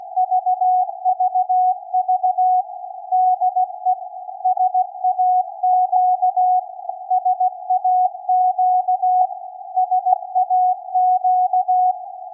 - Rahmenantenne mit selbstgebauten Vorverstärker und Laptop mit Panoramic SAQ 0.94